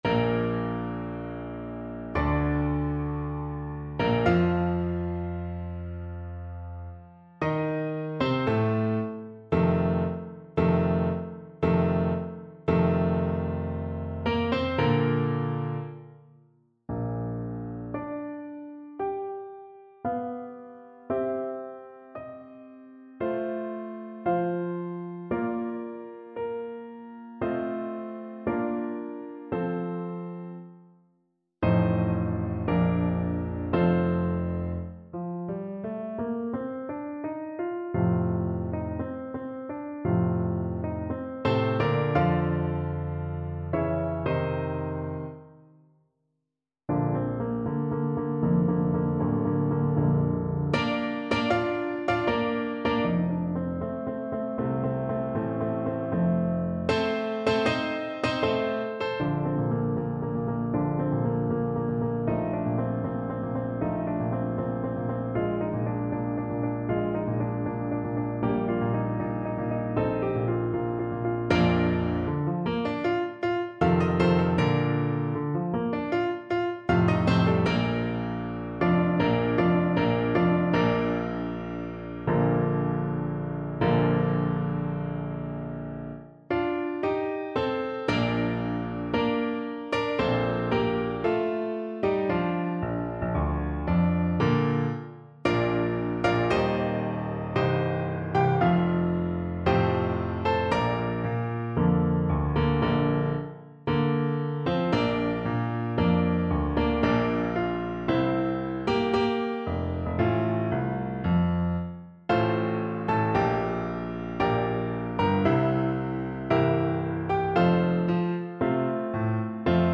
Maestoso ( = 76)
4/4 (View more 4/4 Music)
Traditional (View more Traditional Voice Music)